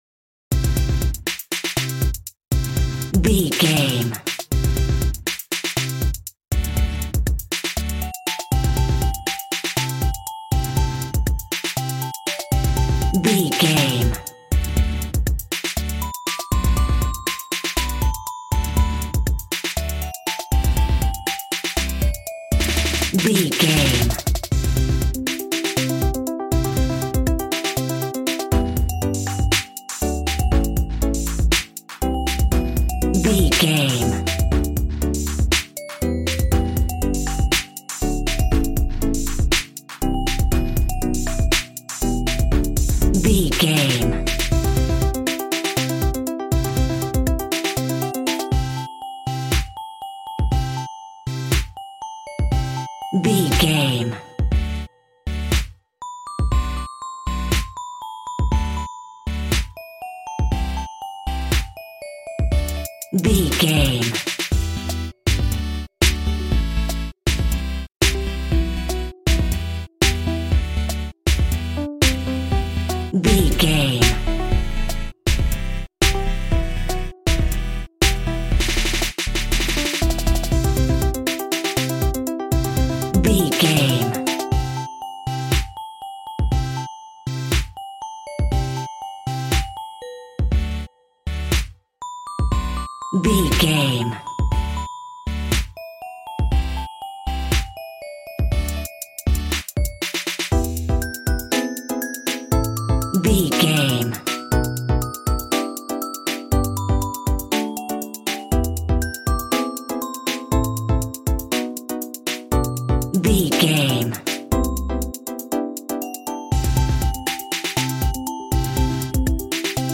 Aeolian/Minor
calm
smooth
synthesiser
piano